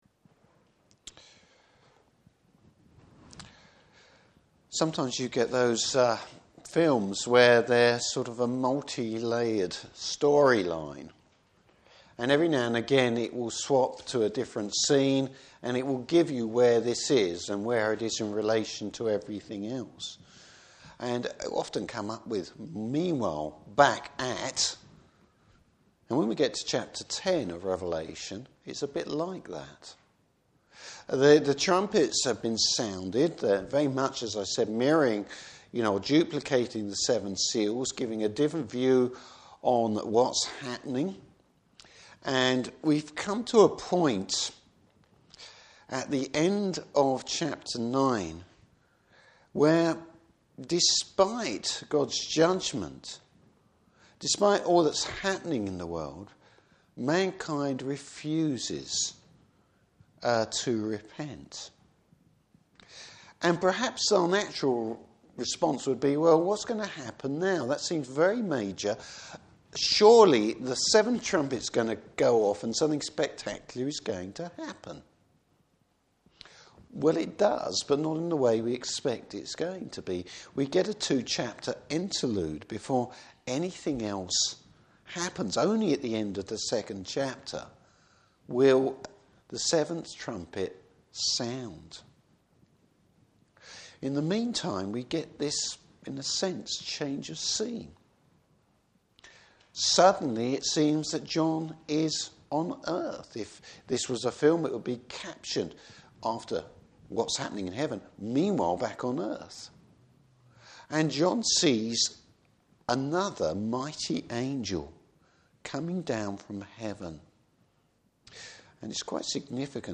Service Type: Evening Service Bible Text: Revelation 10.